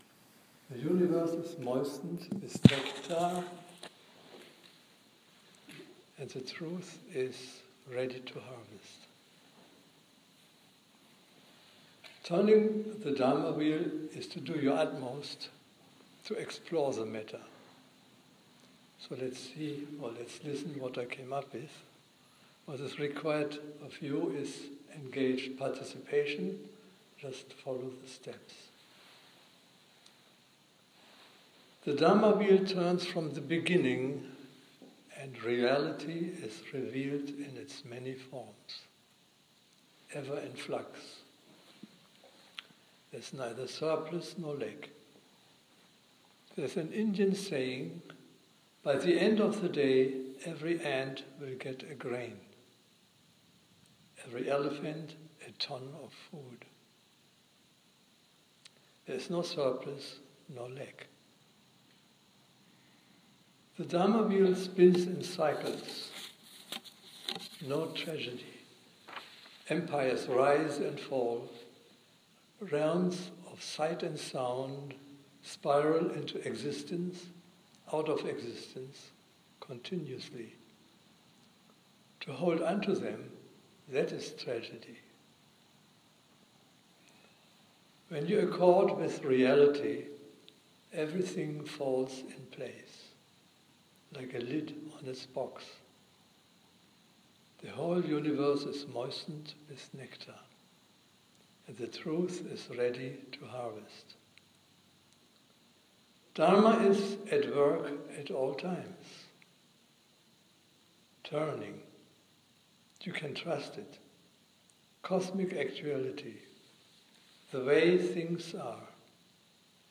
The Truth is Ready to Harvest : Dharma Talk